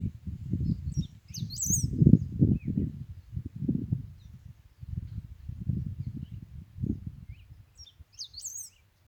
Pampa Finch (Embernagra platensis)
Location or protected area: Concordia
Detailed location: Camino a El Duraznal
Condition: Wild
Certainty: Observed, Recorded vocal